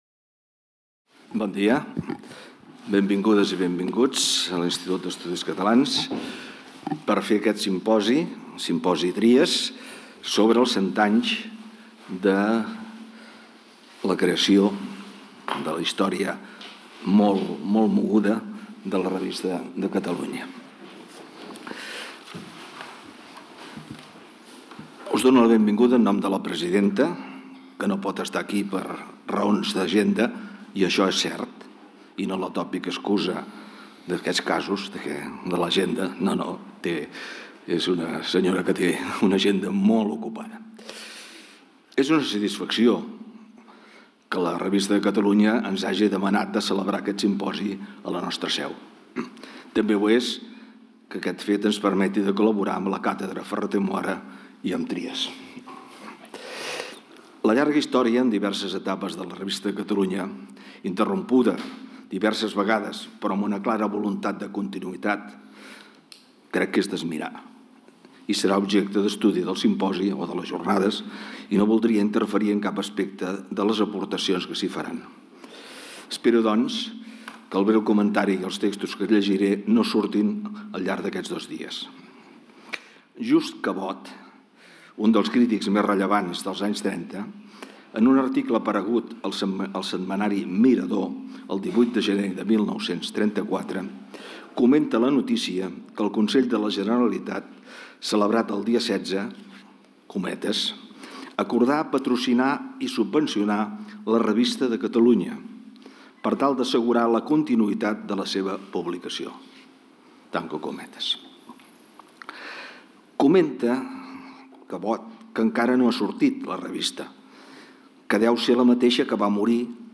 Inauguració del Simposi